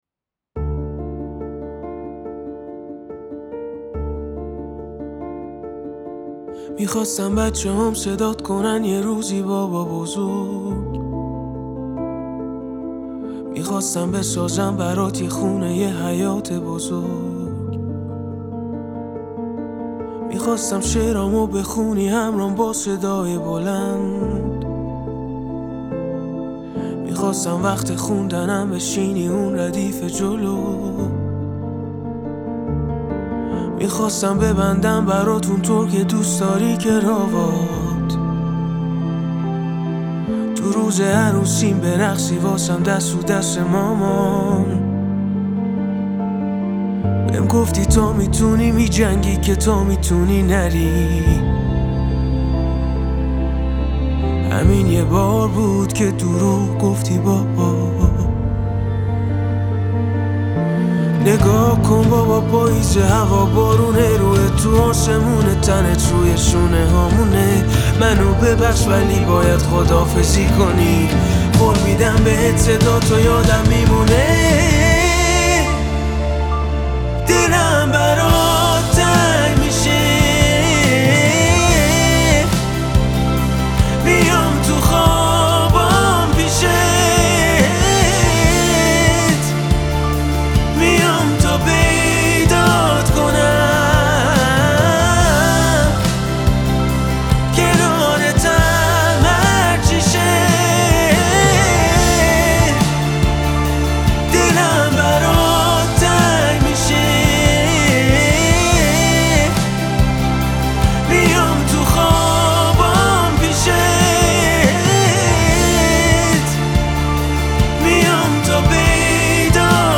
پاپ غمگین